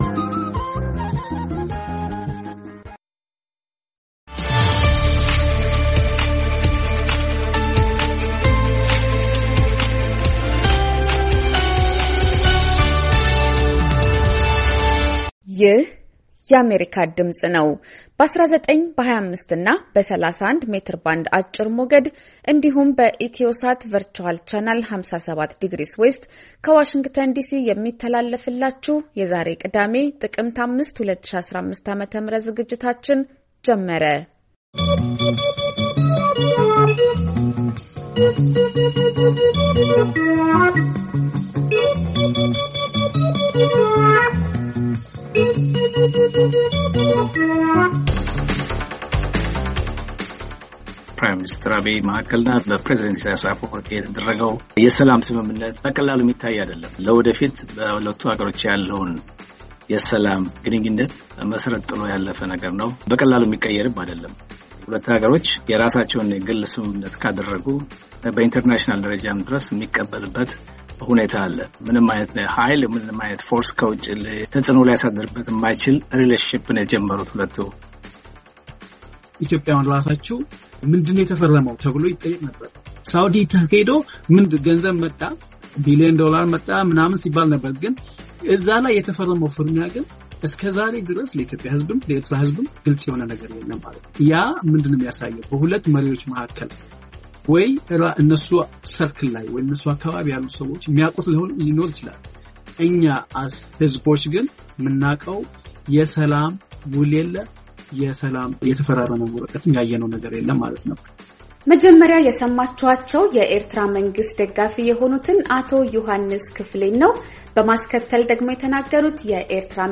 ቅዳሜ፡-ከምሽቱ ሦስት ሰዓት የአማርኛ ዜና